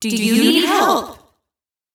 DoYouNeedHelp_2.wav